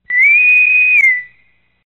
Kategorien: Soundeffekte